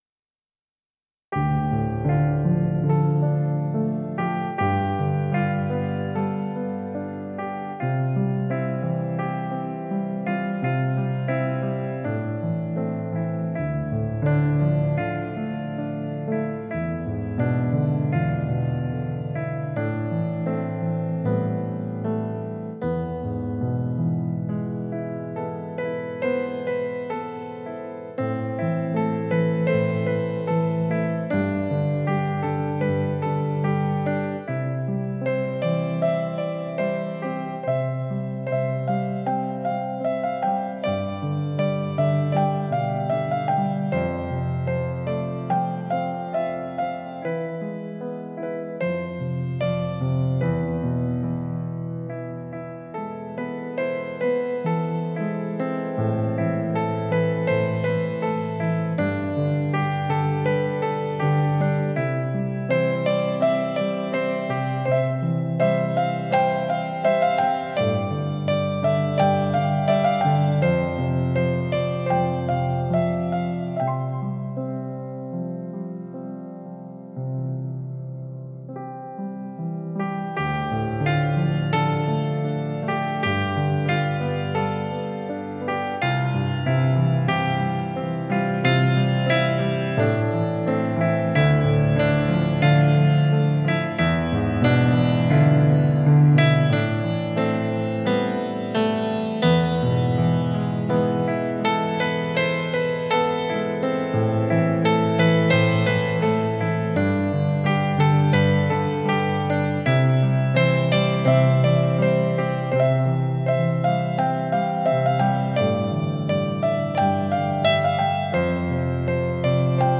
Zde je pár skladeb hraných na mém bývalém klavíru: